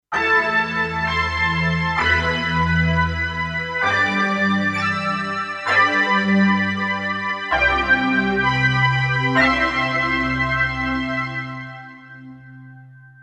Double set of cards for both synthesizer Roland JD-800 or module JD-990.